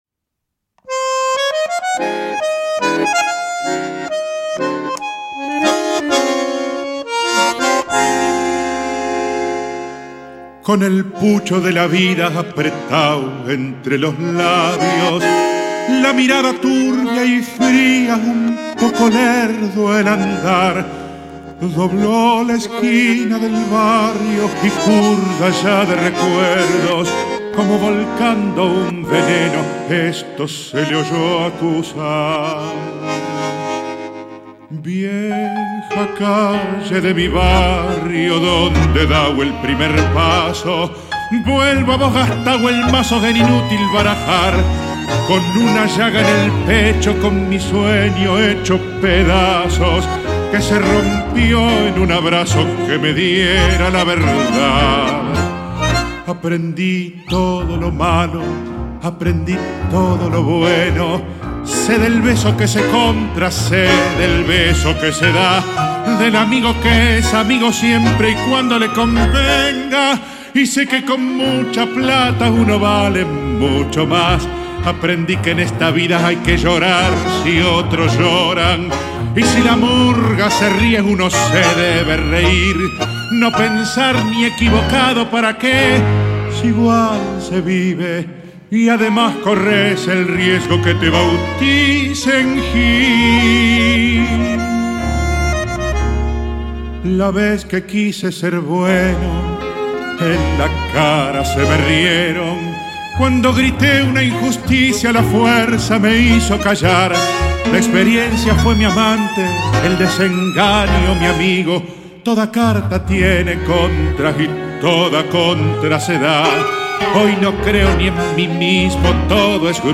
tangos